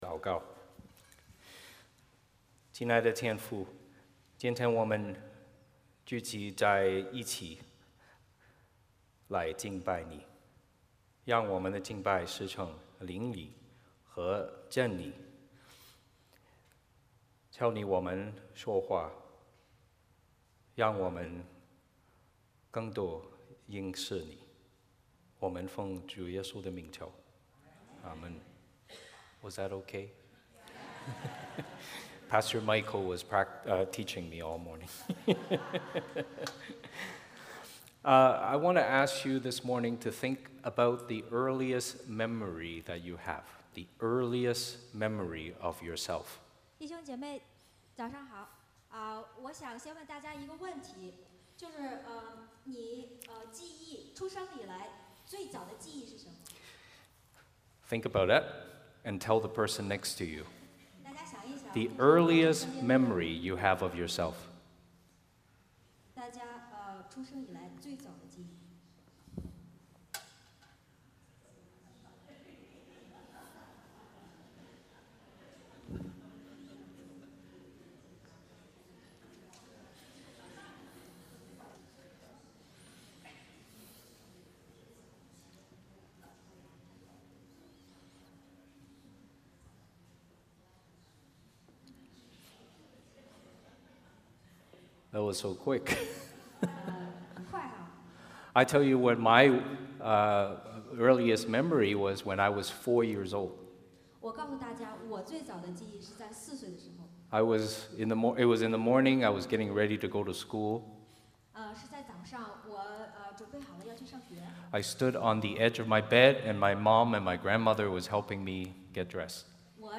Service Type: 主日崇拜 欢迎大家加入我们的敬拜。